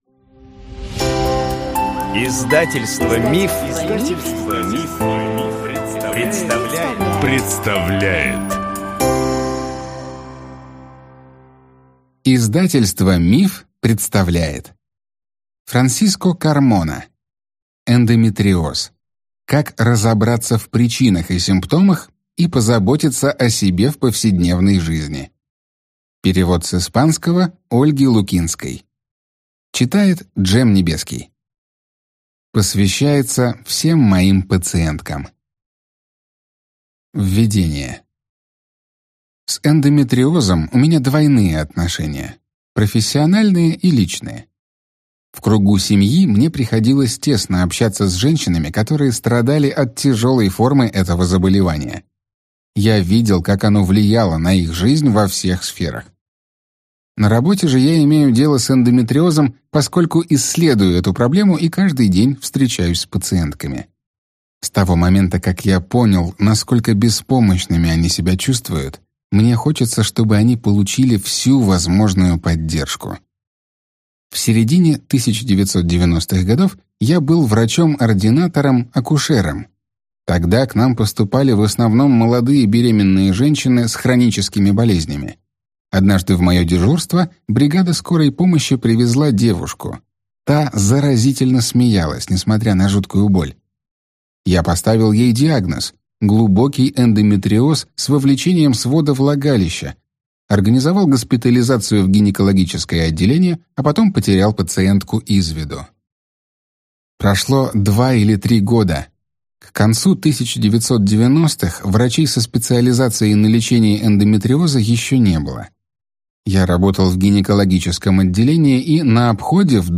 Аудиокнига Эндометриоз. Как разобраться в причинах и симптомах и позаботиться о себе в повседневной жизни | Библиотека аудиокниг